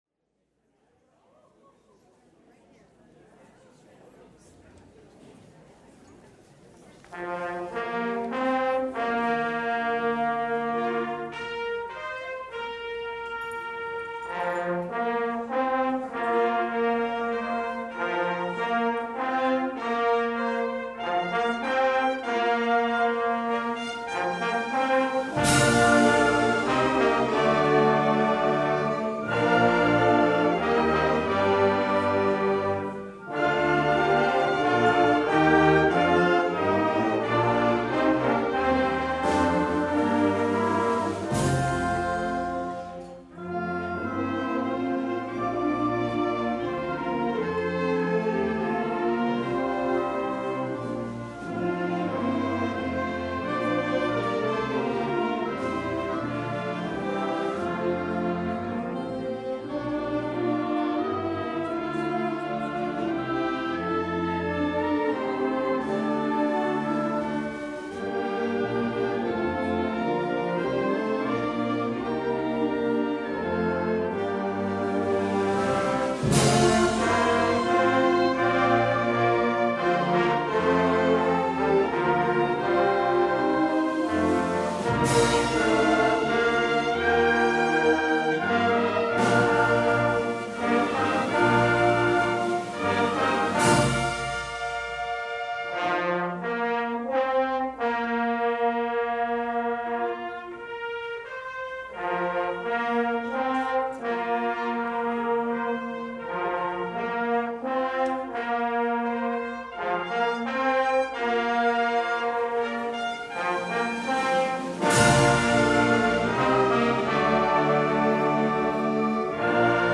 Full Concert audio mp3
Winter concert – Dec. 9th / 2018 “Always In Style”